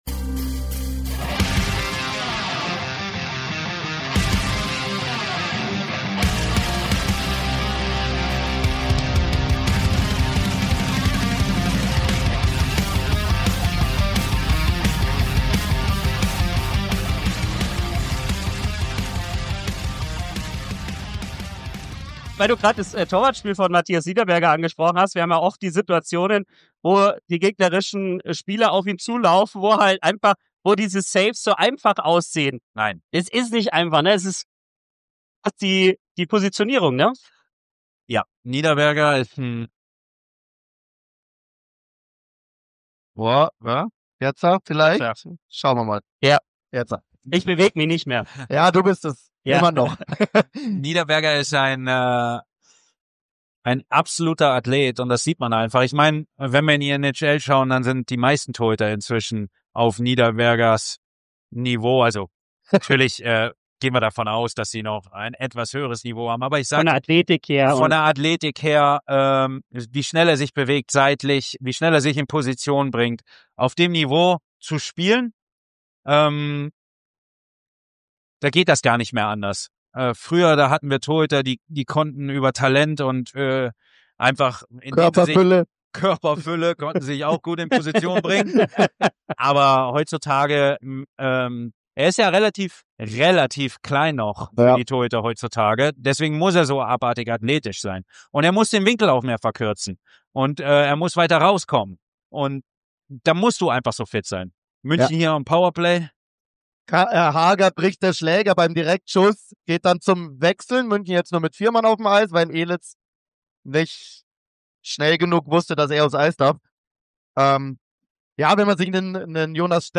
- Original-Radioübertragung aus dem Jahr 2024